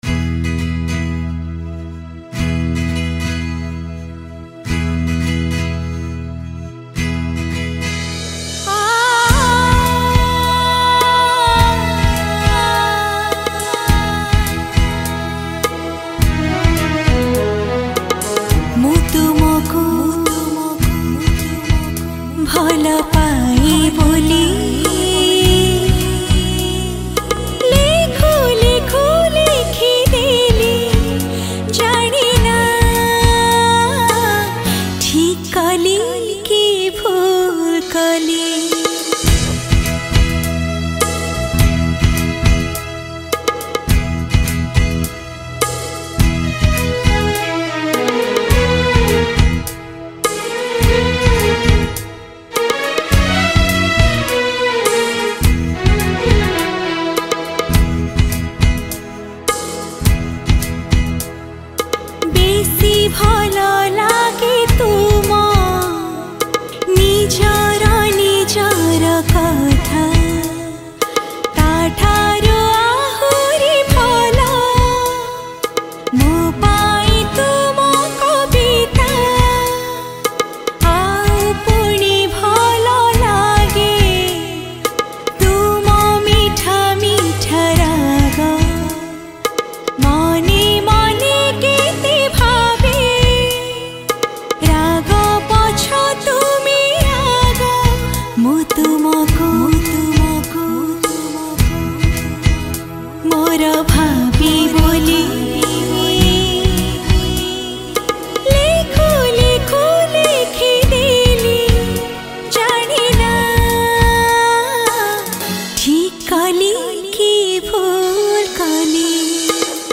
Odia Songs